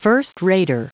Prononciation du mot first-rater en anglais (fichier audio)
Prononciation du mot : first-rater